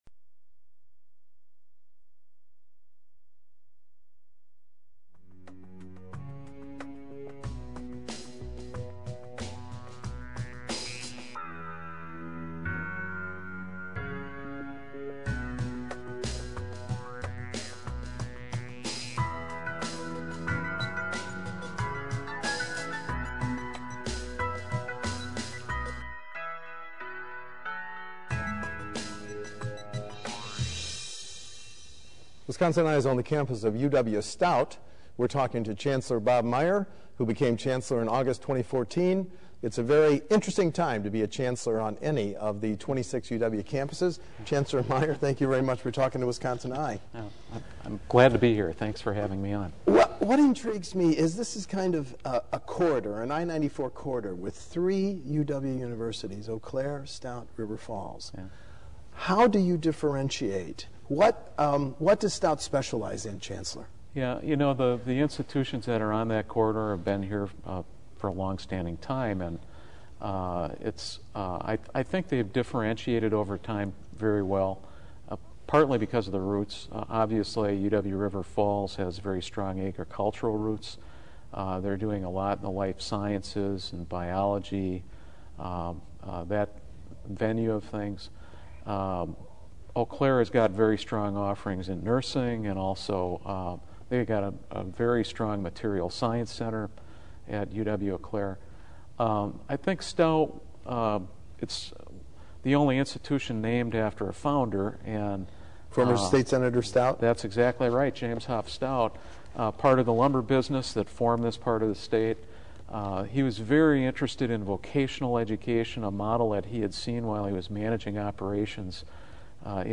interviewed University of Wisconsin-Stout Chancellor Robert Meyer at the UW-Stout campus in Menomonie, WI.